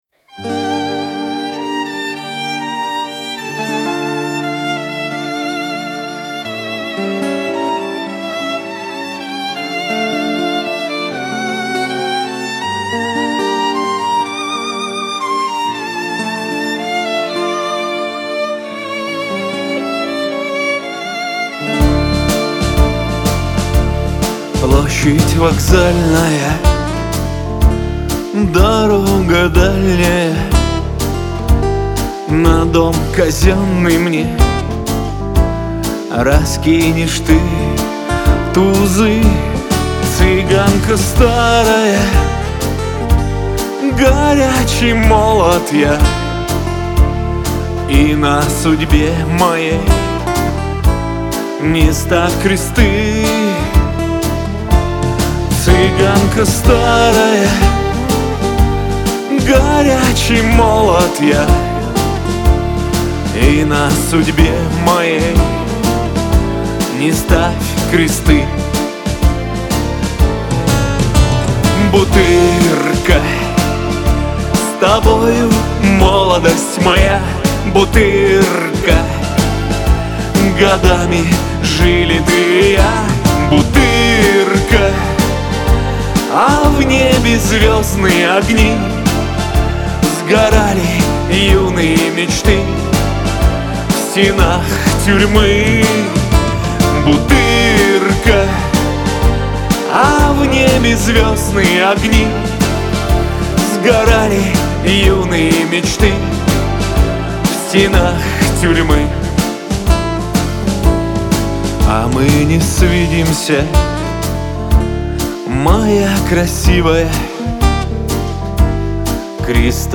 Шансон
грусть, Лирика